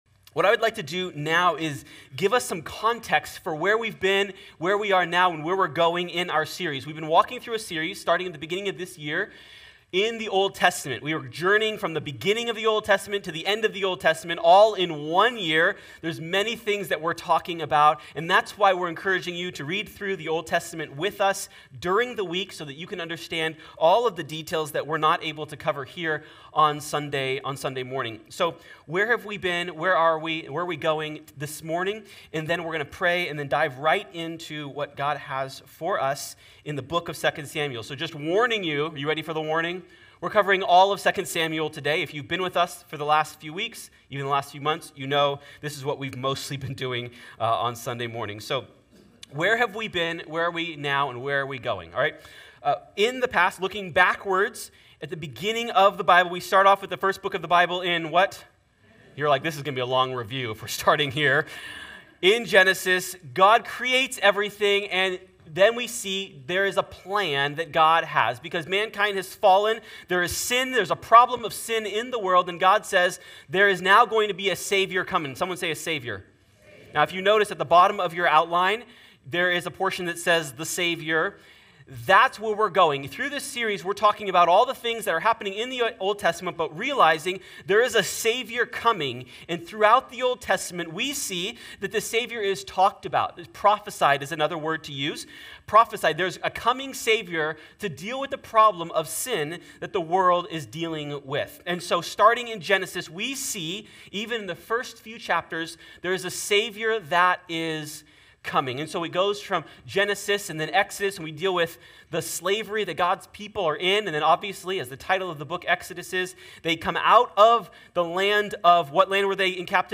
Join us as our Elders teach and we read through the entire Old Testament in one year!